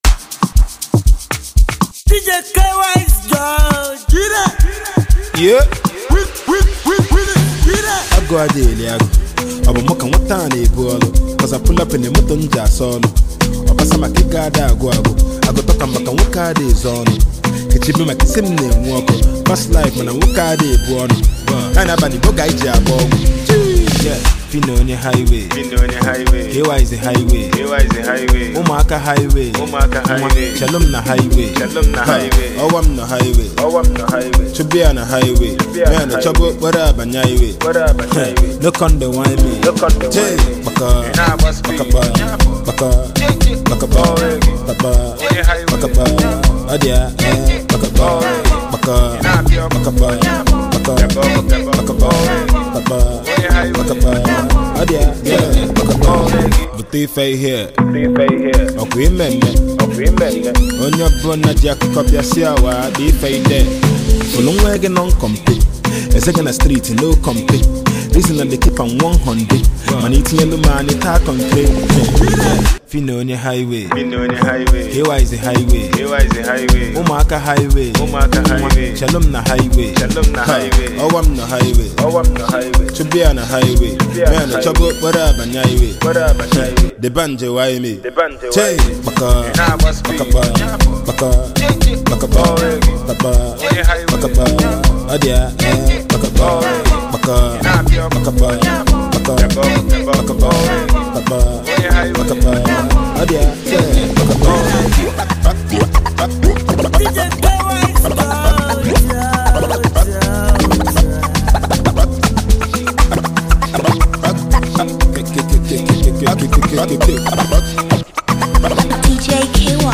Amapiano influenced record